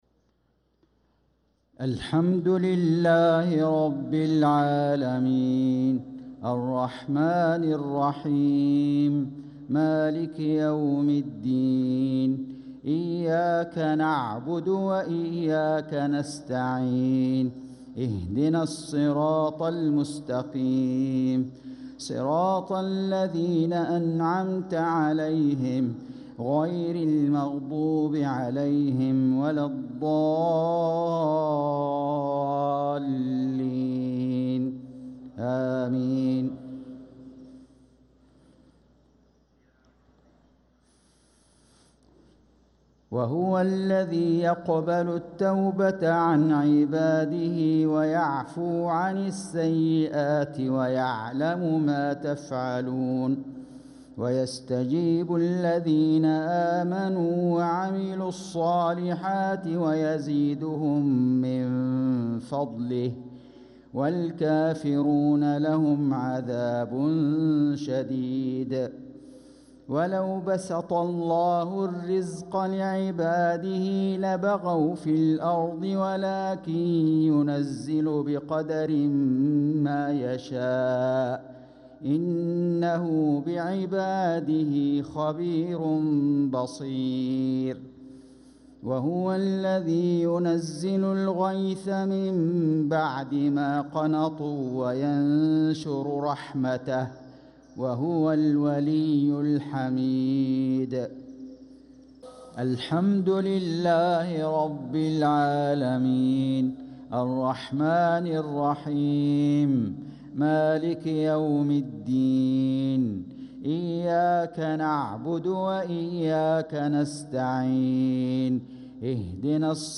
صلاة المغرب للقارئ فيصل غزاوي 23 جمادي الأول 1446 هـ
تِلَاوَات الْحَرَمَيْن .